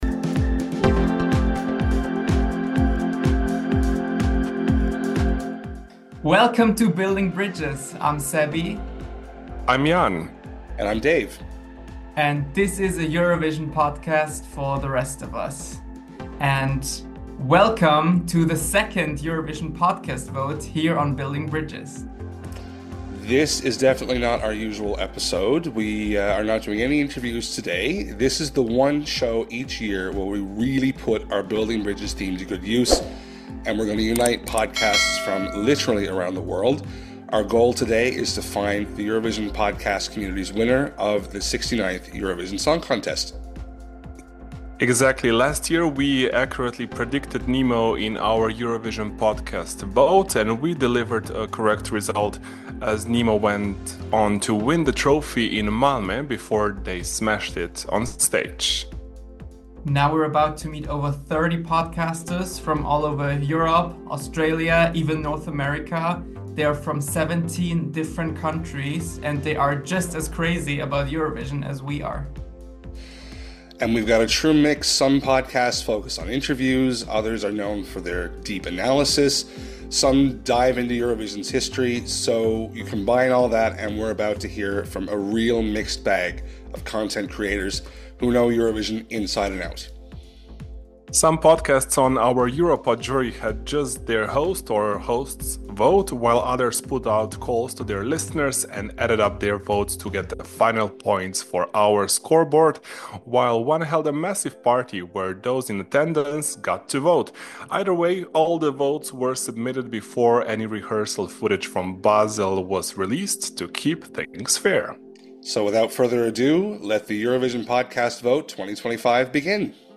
joined us from their homes in Italy to discuss the inspiration behind the project, the challenges of preserving cultural identity while experimenting with modern production, and the role of nostalgia in their music.